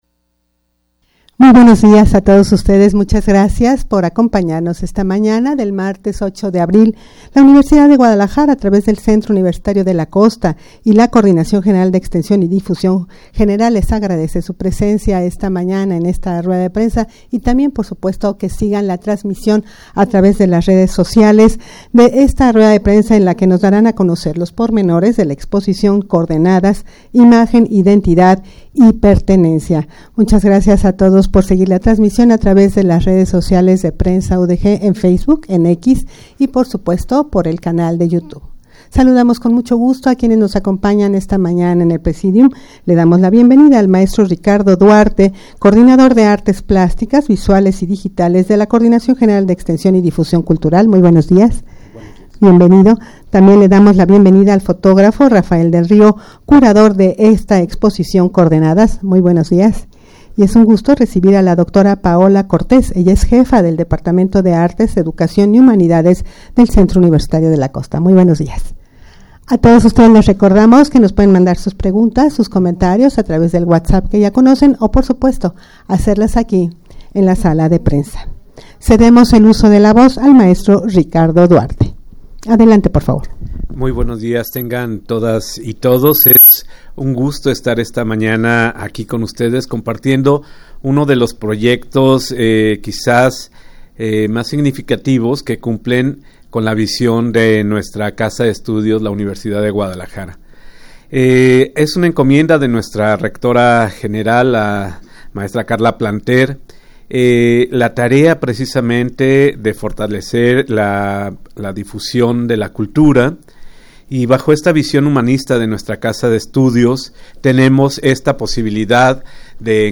Audio de la Rueda de Prensa
rueda-de-prensa-para-dar-a-conocer-la-exposicion-coordenadas-imagen-identidad-y-pertenencia.mp3